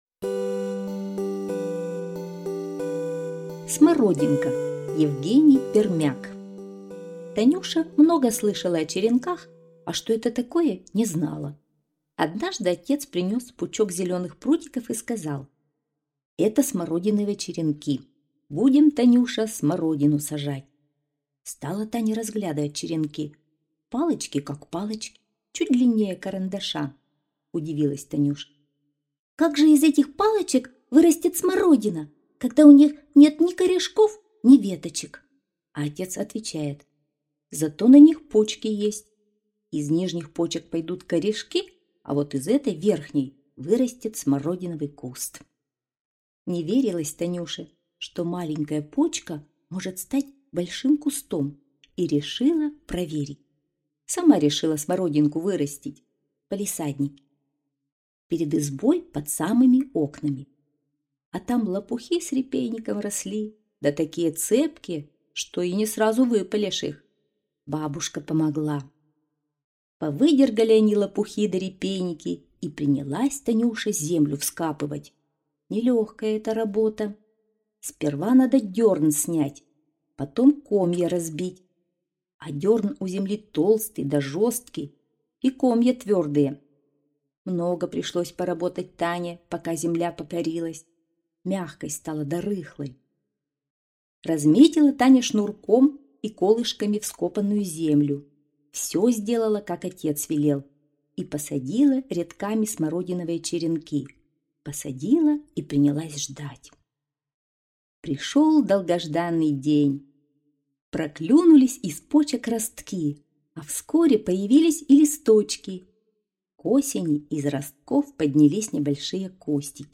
Смородинка - аудио рассказ Пермяка Е.А. Рассказ о девочке Танюше, которая сама вырастила смородину из черенков.